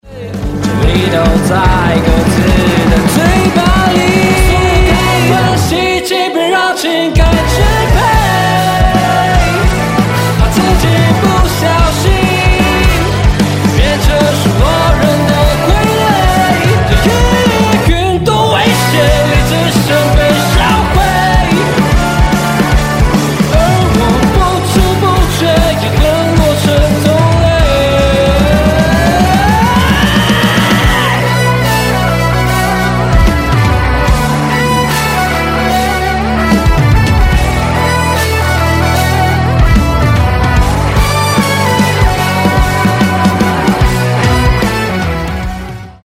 Alternative rock, Chinese pop